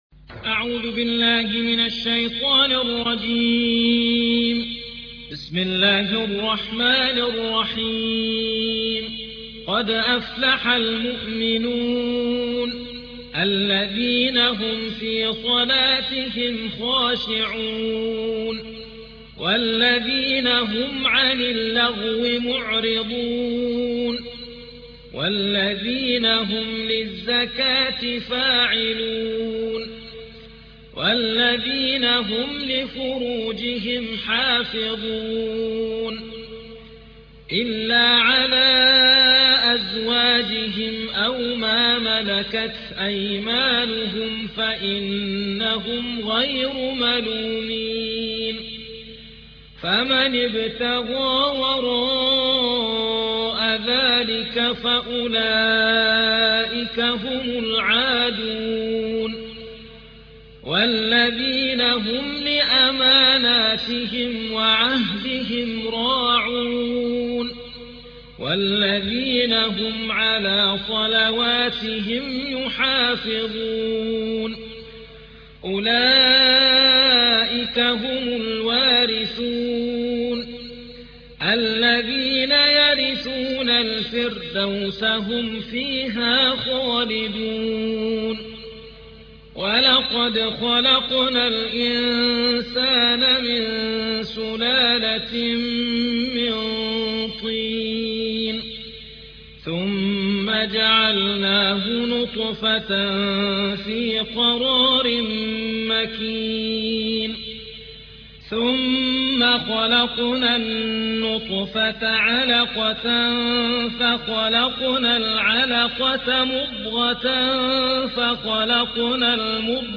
23. سورة المؤمنون / القارئ